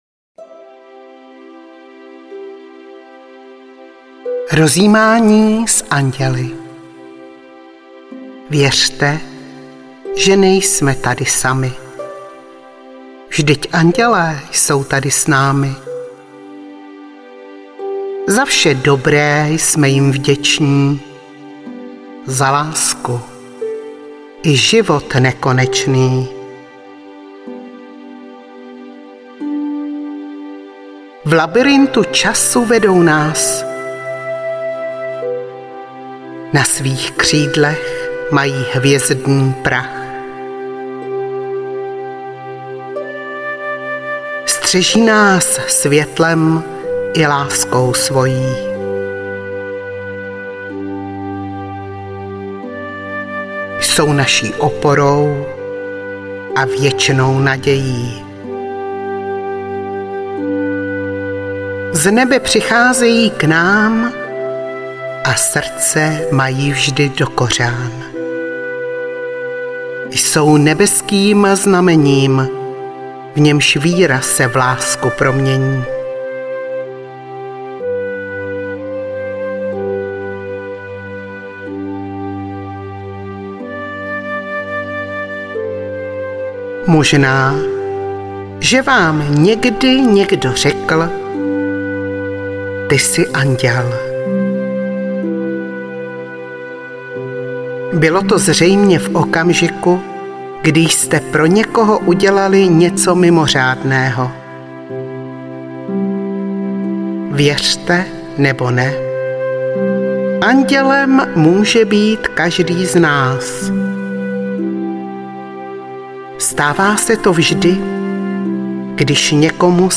Audiokniha
24 andělských meditací doprovázených poetickou relaxační hudbou